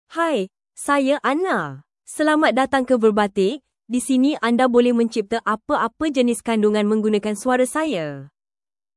Anna — Female Malay (Malaysia) AI Voice | TTS, Voice Cloning & Video | Verbatik AI
AnnaFemale Malay AI voice
Voice sample
Female
Anna delivers clear pronunciation with authentic Malaysia Malay intonation, making your content sound professionally produced.